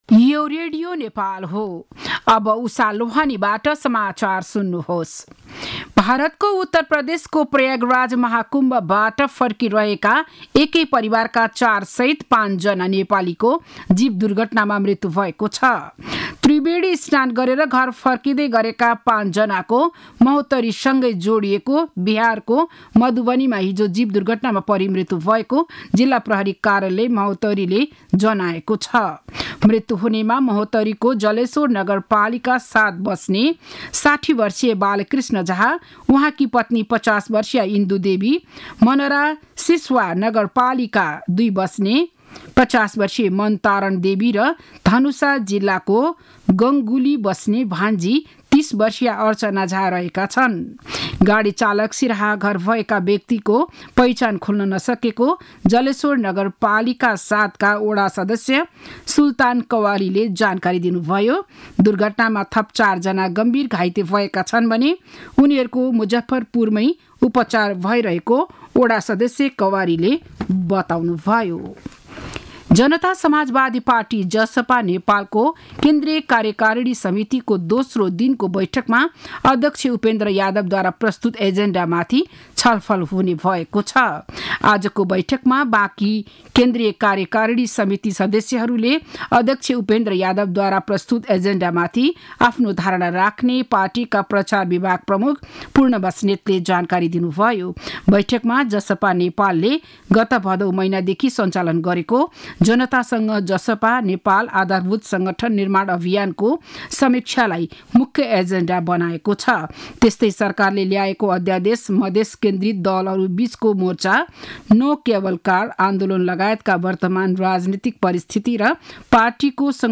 बिहान ११ बजेको नेपाली समाचार : २१ माघ , २०८१
11-am-news-.mp3